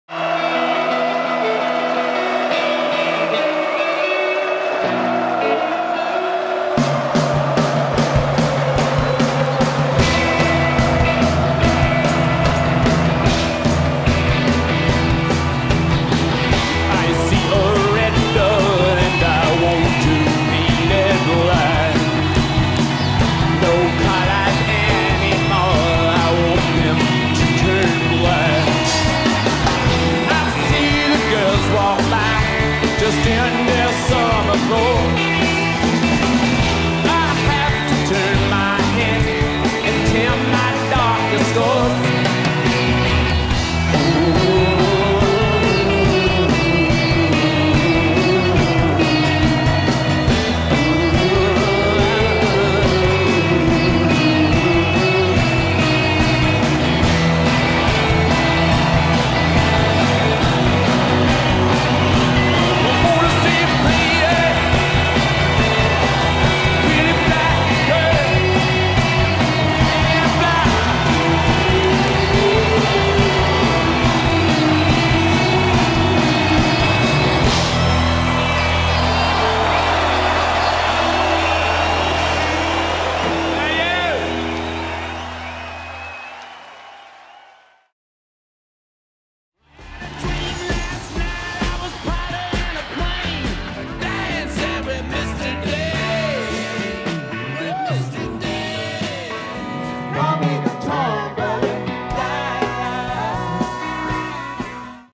Live In Argentina